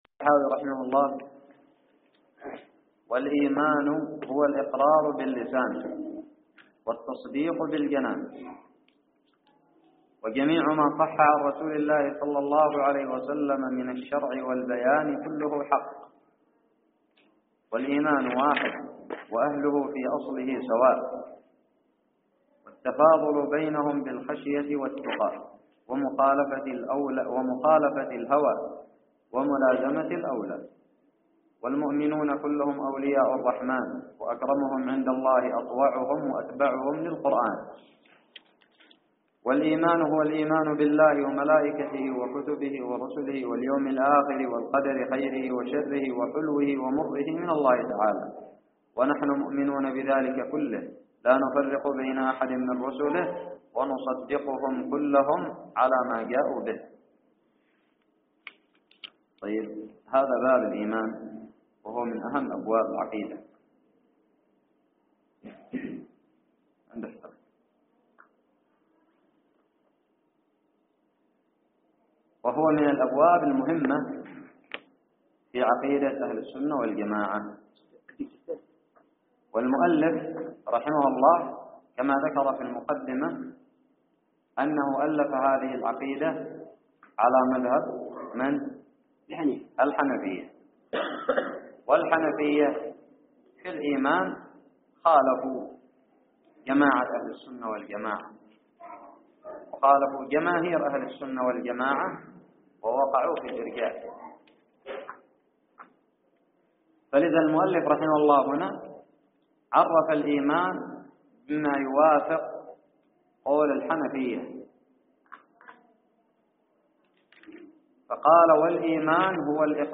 الدرس الثلاثون من شرح العقيدة الطحاوية
ألقيت في دار الحديث بدماج